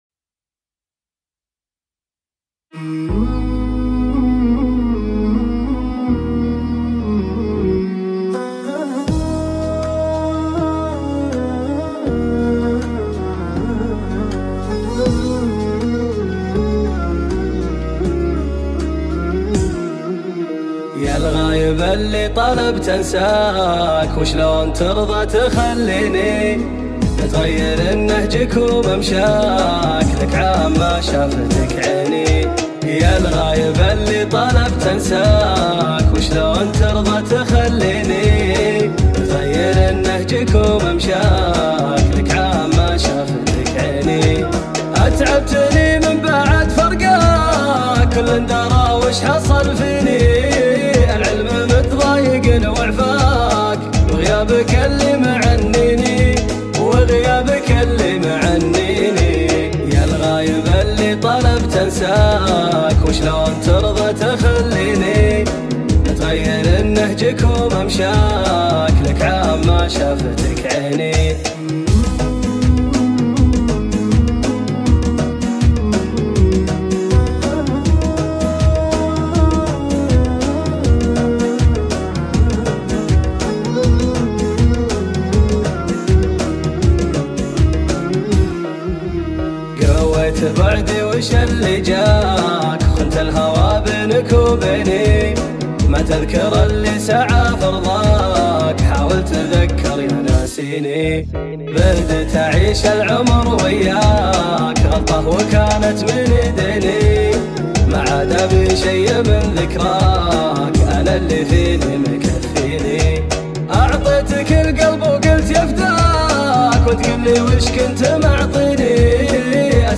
اغانى اغاني خليجيه